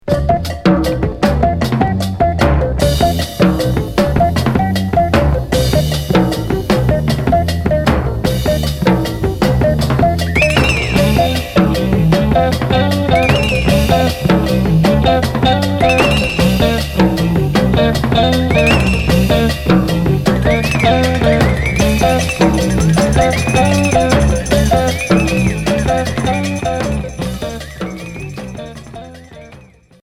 Groove pop Sixième 45t retour à l'accueil